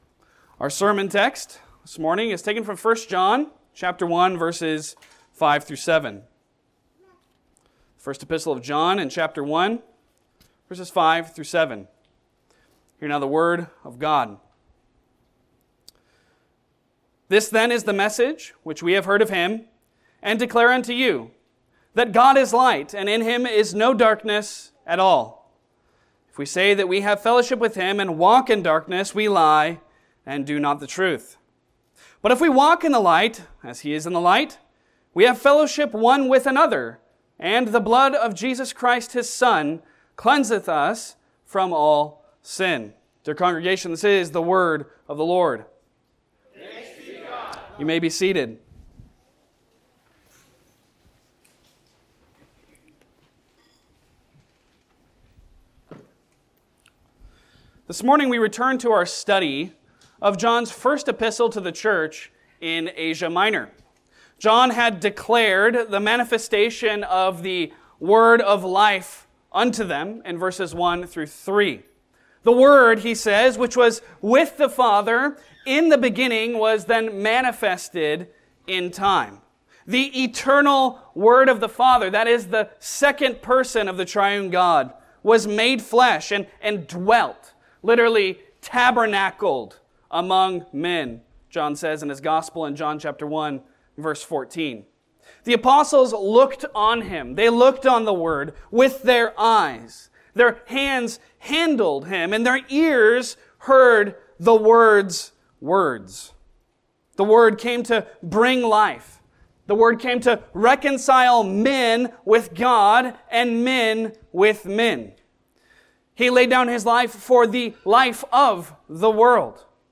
Passage: 1 John 1:5-7 Service Type: Sunday Sermon